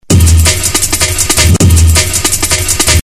Jungle Loop 1